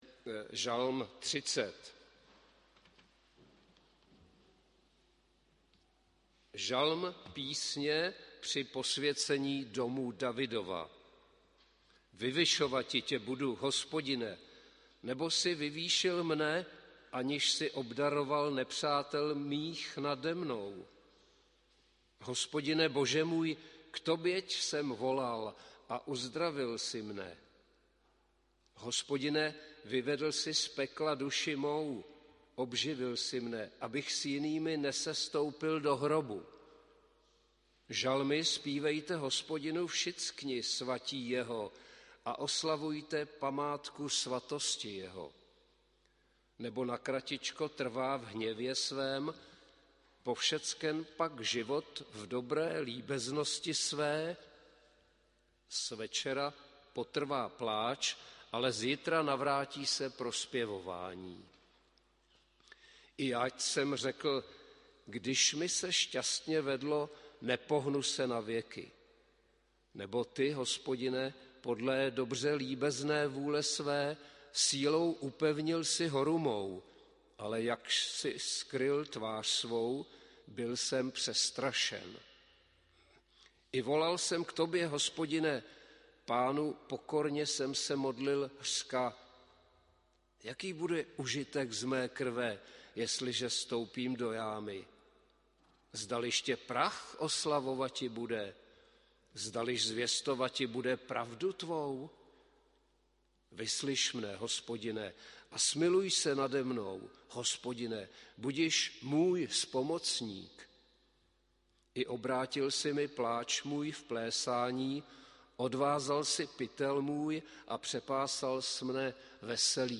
Bohoslužby 8. 5. 2022 • Farní sbor ČCE Plzeň - západní sbor